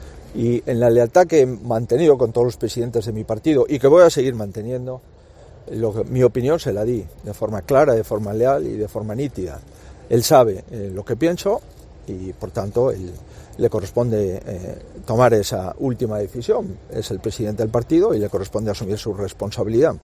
"Él sabe lo que pienso y, por tanto, le corresponde tomar esa última decisión", ha dicho Feijóo en San Cibrao das Viñas (Ourense) a su salida de una visita a la fábrica de Aceites Abril.
El dirigente gallego, a preguntas de los periodistas, ha comentado que su opinión se la dio "de forma clara, de forma leal y de forma nítida" a Casado.